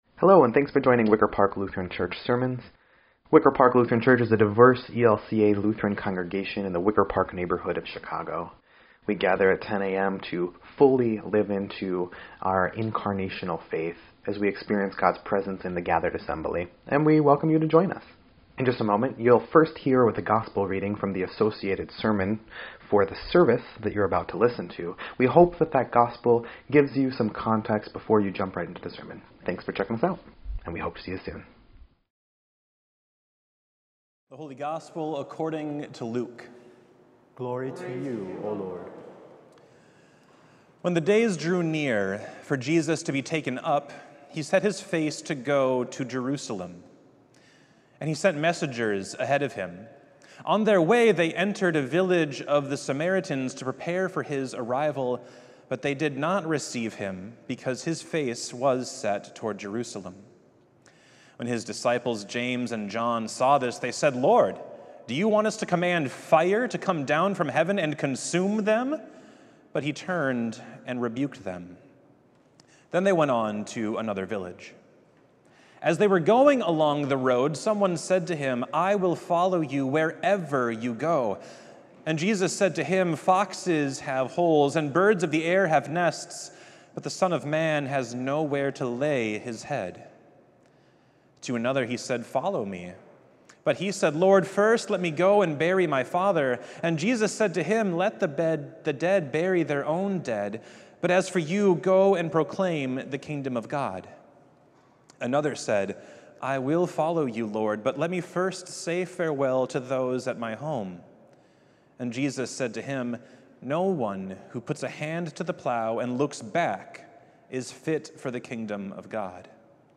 6.29.25-Sermon_EDIT.mp3